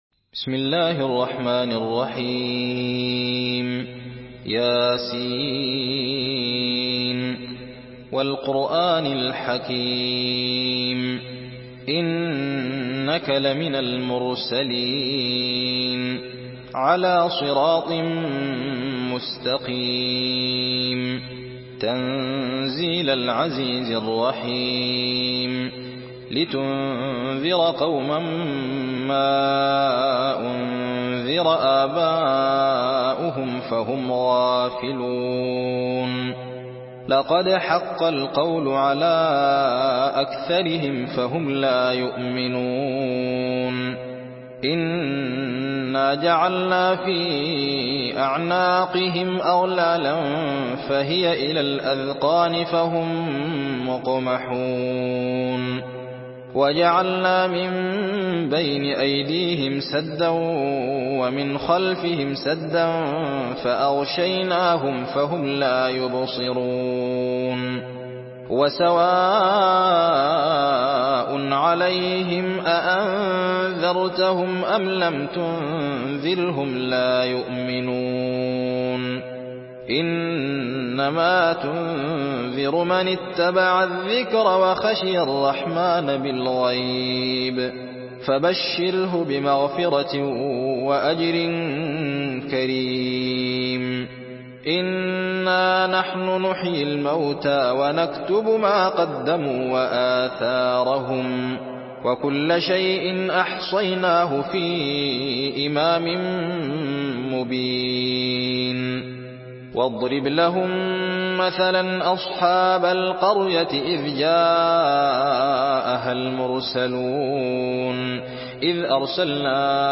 سورة يس MP3 بصوت الزين محمد أحمد برواية حفص
مرتل